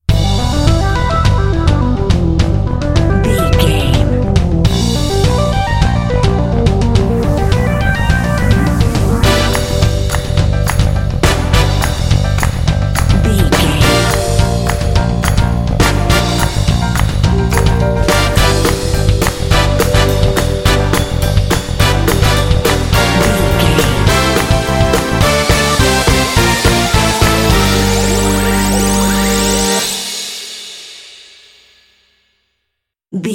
Fast paced
Aeolian/Minor
dark
groovy
funky
synthesiser
drums
bass guitar
brass
electric guitar
synth-pop